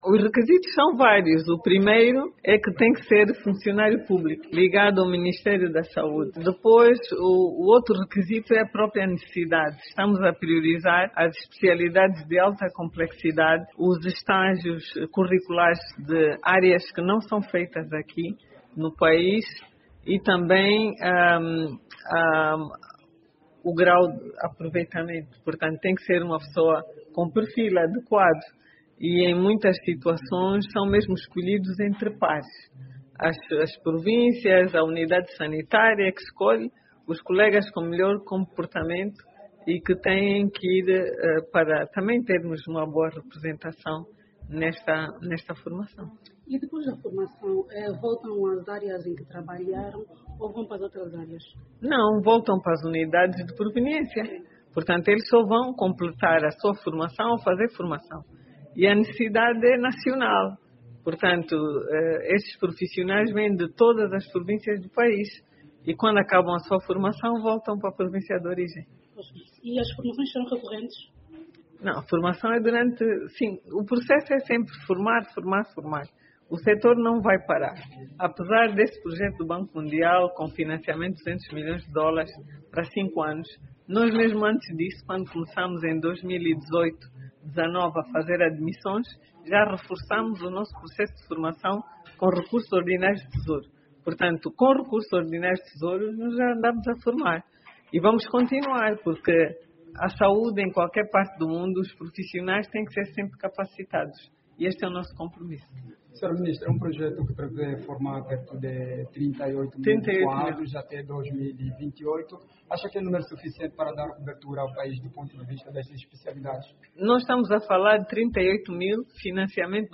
O acto oficial de acolhimento e orientação dos bolseiros decorreu no Complexo Hospitalar General do Exército Pedro Maria Tonha “Pedalé” e foi presidido pela ministra da Saúde, Sílvia Lutucuta, que considerou o momento “histórico” para o sector da Saúde em Angola.
MINISTRA-DA-SAUDE-RA.mp3